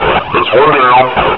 /hl2/sound/npc/combine_soldier/test/near/
player_dead3.ogg